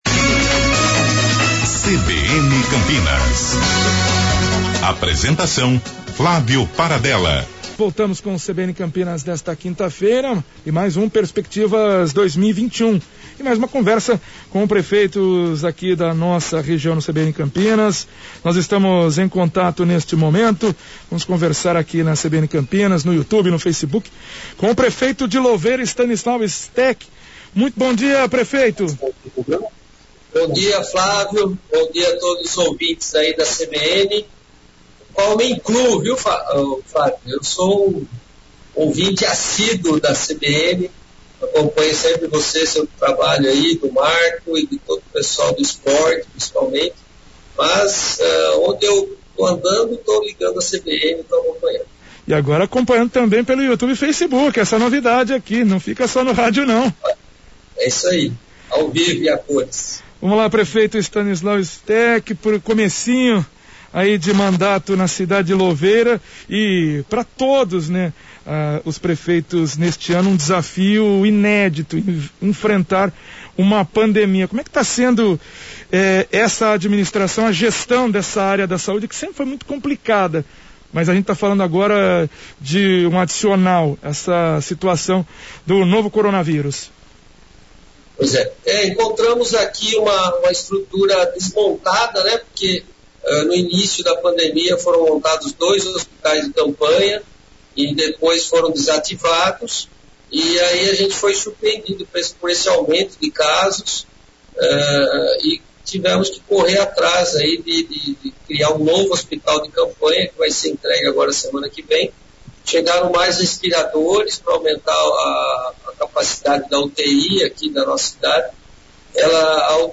O CBN Campinas teve a participação do prefeito de Louveira, Estanislau Steck, que explicou os desafios para o início de mandato e o enfrentamento ao novo coronavírus.